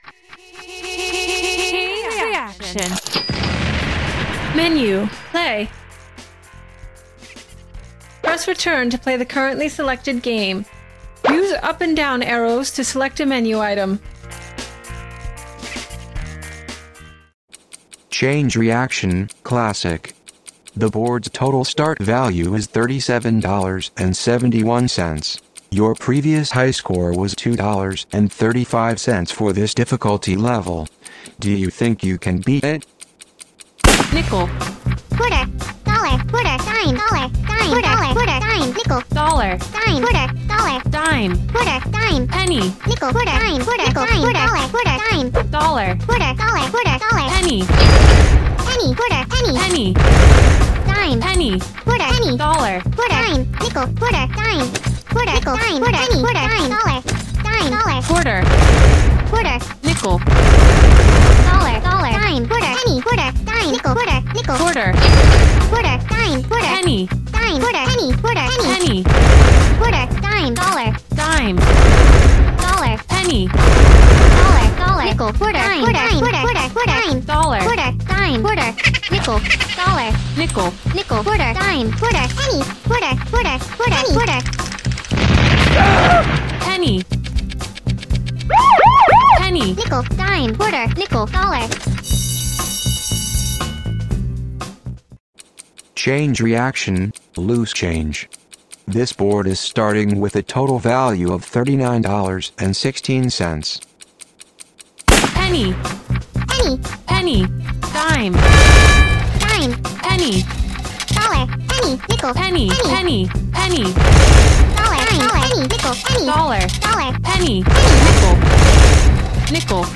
Note that the TTS voice in the audio demo is a Mac voice.